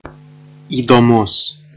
Ääntäminen
IPA: [in.te.ɾǝ.sɑnt]